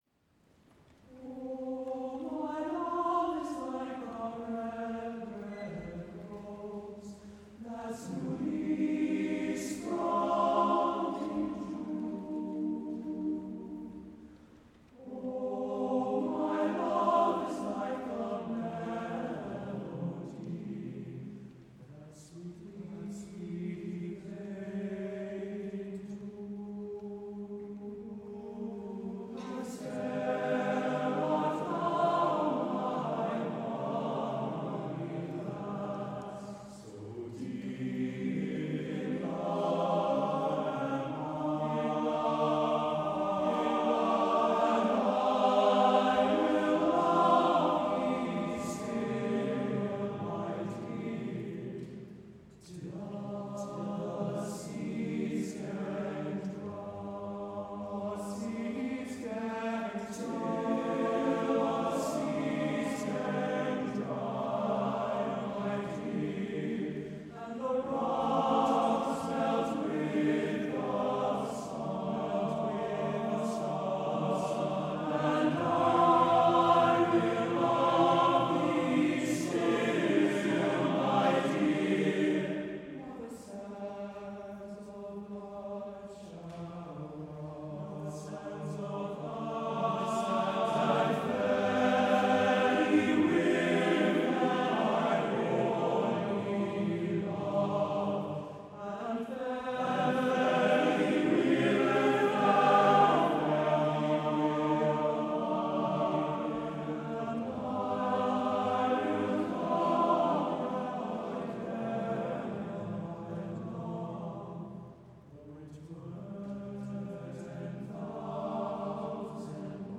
MEN'S CHORUS
TTBB a cappella